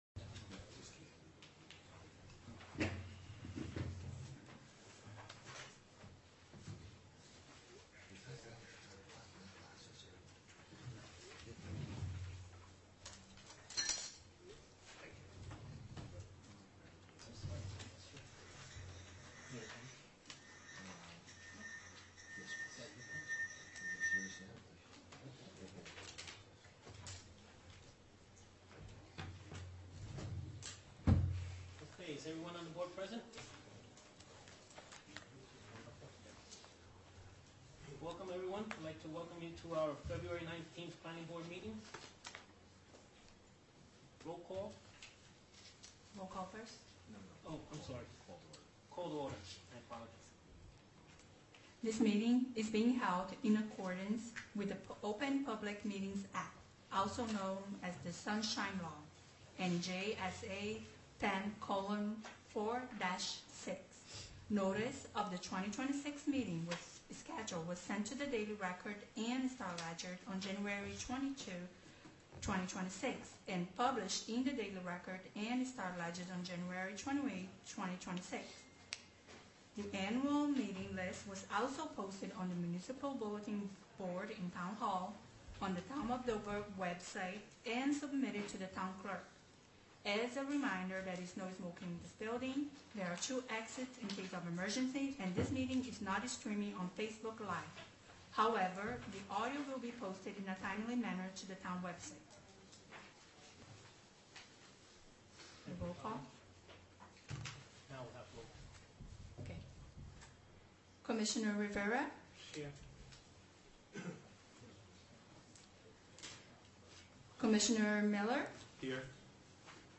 Meeting Type : Planning Board